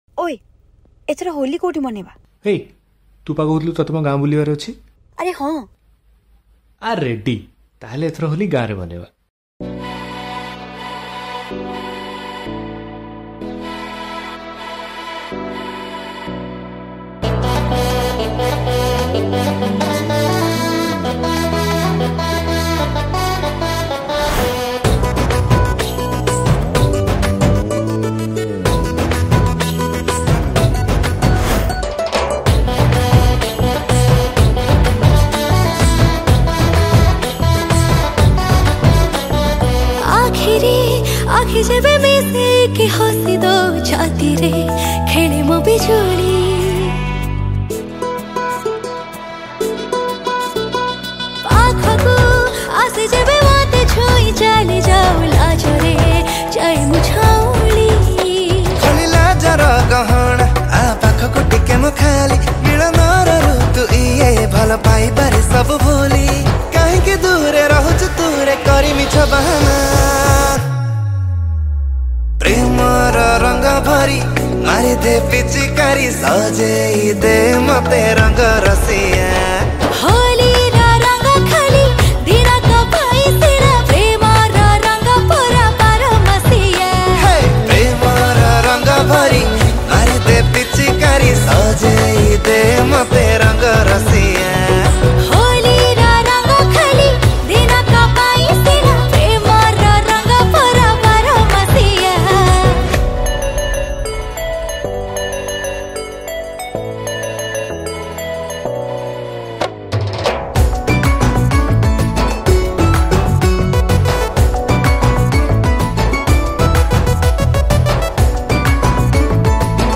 Holi Spcial Mp3 Song Songs Download